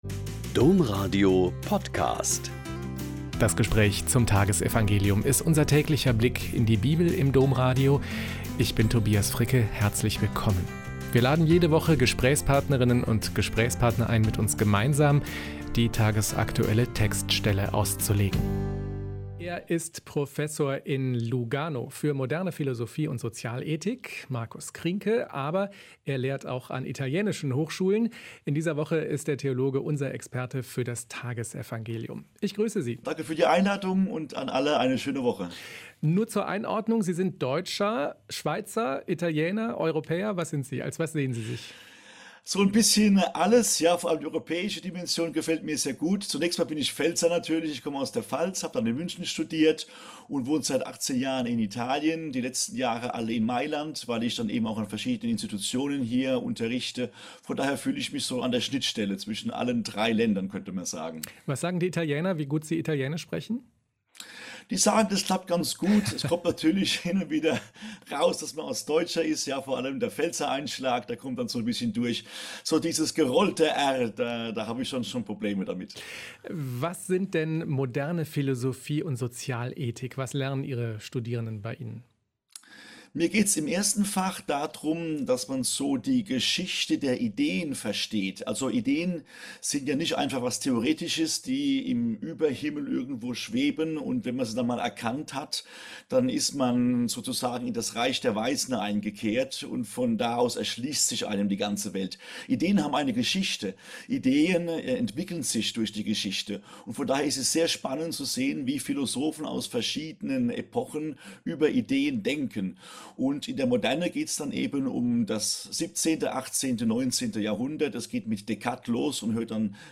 Joh 14,21-26 - Gespräch